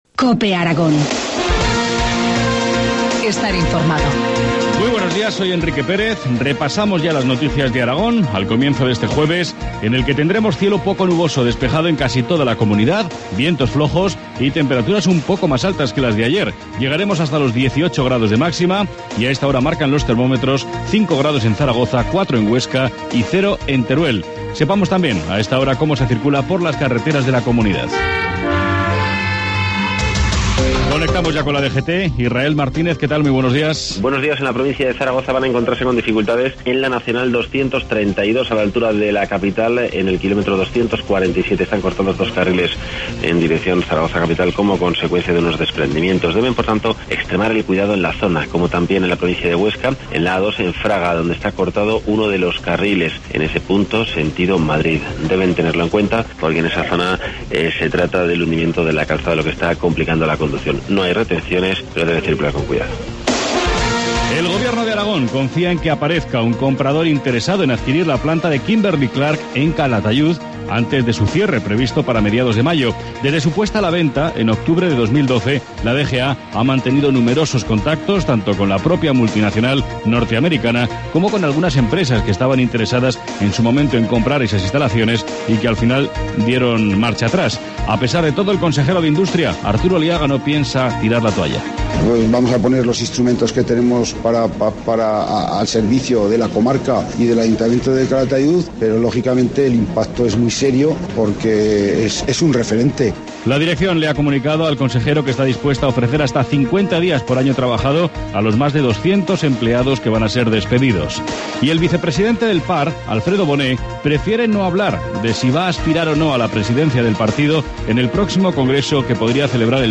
Informativo matinal, jueves 21 de marzo, 7.25 horas